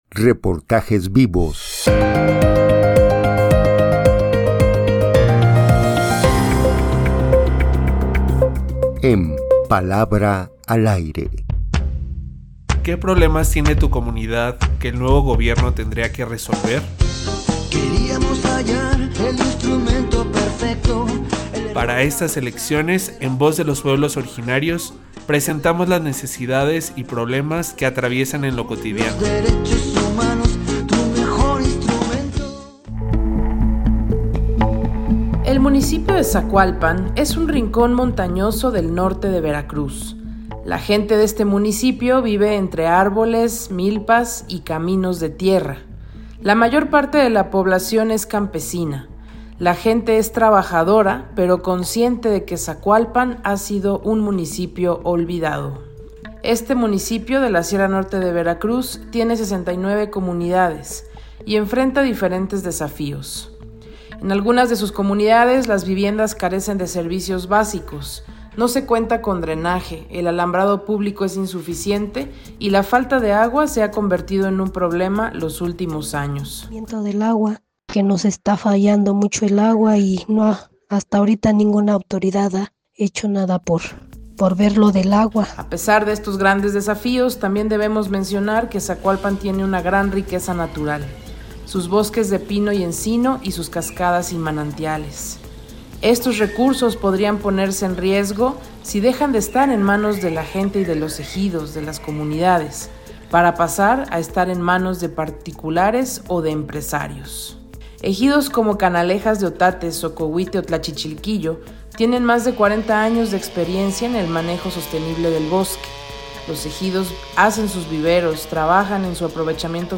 escucha la palabra de las y los campesinos en las distintas comunidades de Zacualpan, Veracruz.